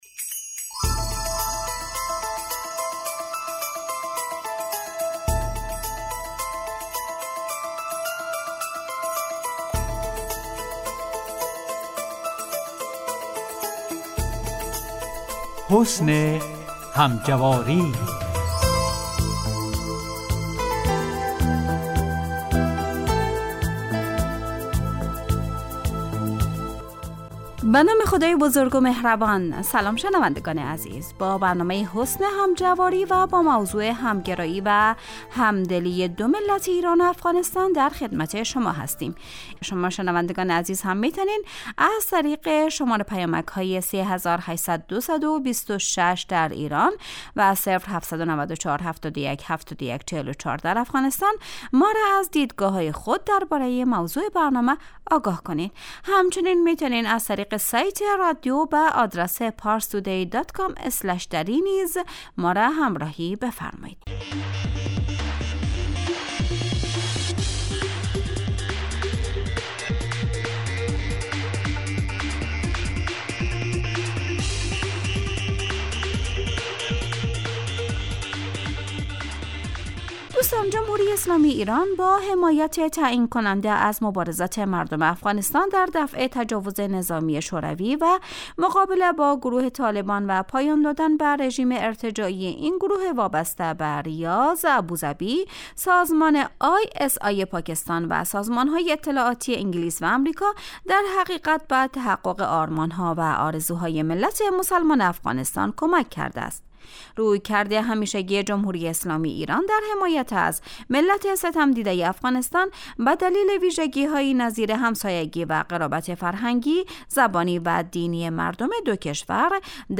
حسن همجواری برنامه ای 15 دقیقه ای است که در روزهای سه شنبه و پنج شنبه ساعت 14:15 به روی آنتن می رود .